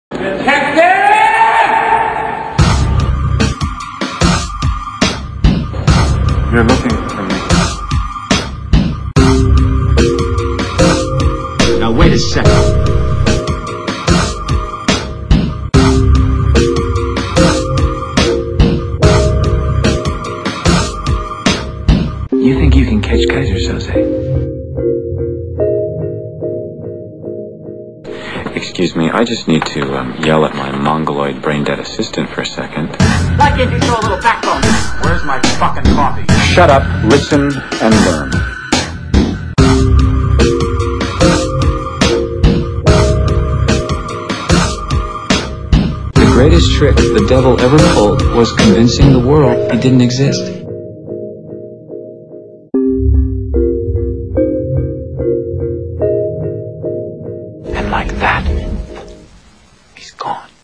. . . THE FUNKY KEVIN MIX THAT PLAYS ON MY START PAGE . . .